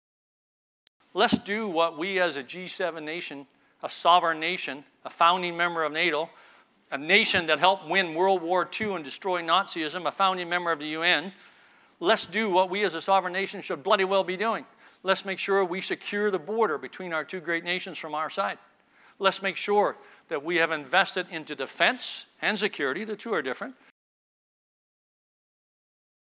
Canadian Armed Forces Retired General Rick Hillier was the keynote speaker at the Saskatchewan Crops Conference in Saskatoon on Tuesday.